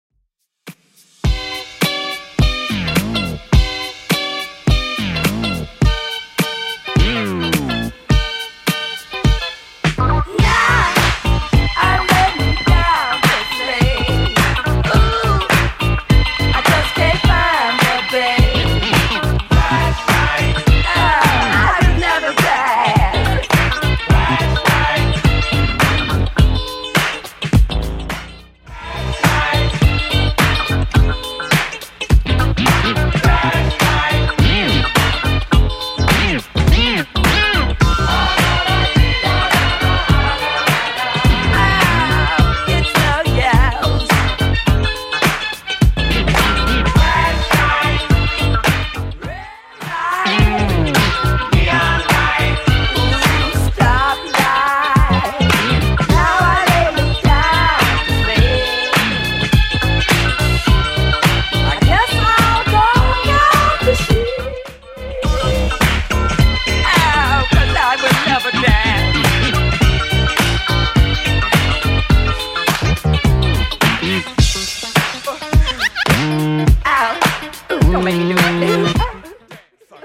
Genre: 80's
BPM: 98